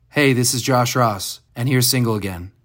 LINER Josh Ross (Single Again) 1
LINER-Josh-Ross-Single-Again-1.mp3